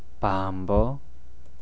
5. Post-nasal Voicing
All stops and affricates become voiced after nasal consonants.
Example: /pa̤npok/ --> [
pã̤mbo] 'book'